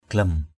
/ɡ͡ɣlʌm/ (đg.) ném, quăng = jeter avec force par terre. glem batuw g*# bt~| ném đá. glem trun aia g*# t~N a`% ném xuống nước.